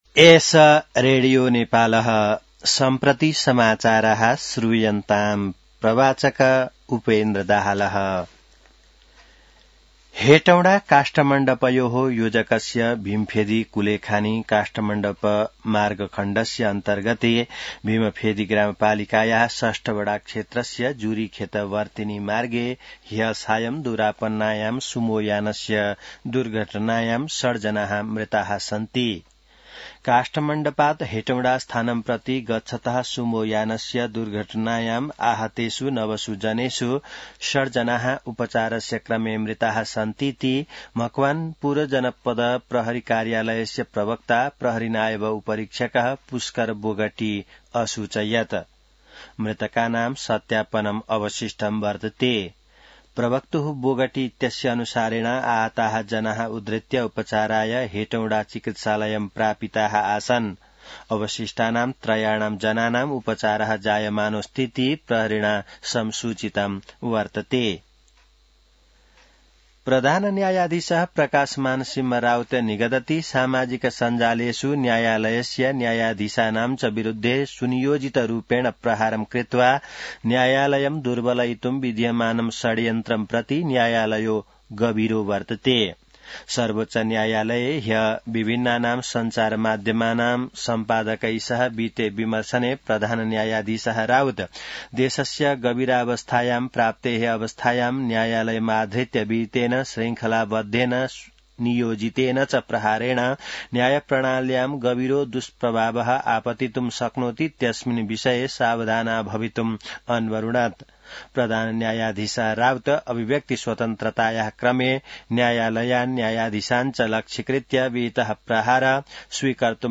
संस्कृत समाचार : २१ पुष , २०८२